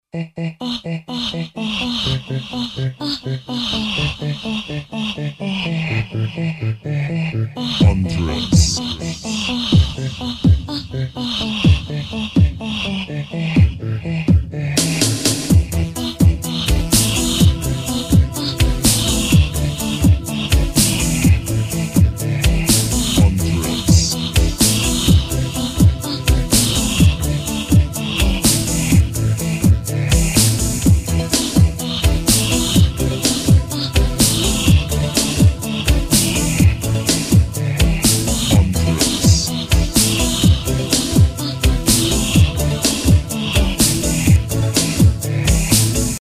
• Качество: 320, Stereo
чувственные
Ахи-вздохи
Неприлично чувственная мелодия